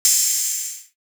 Crashes & Cymbals
Sizzle Sixes.wav